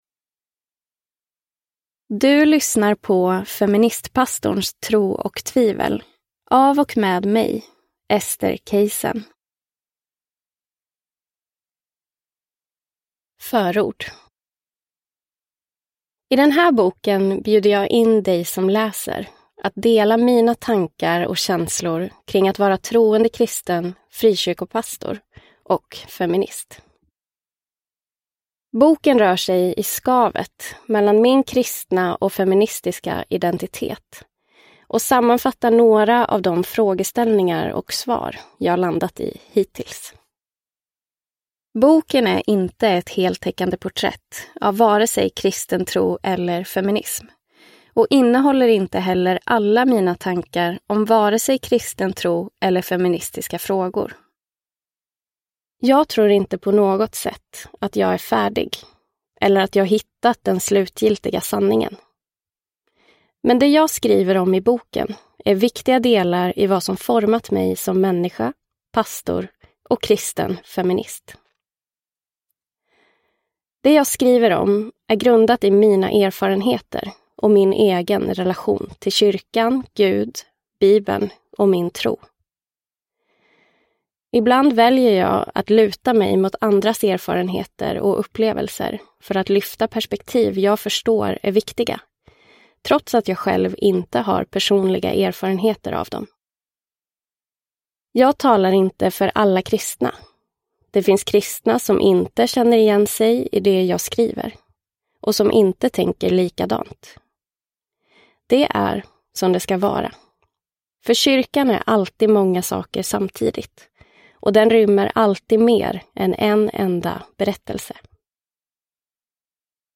Feministpastorns tro och tvivel – Ljudbok – Laddas ner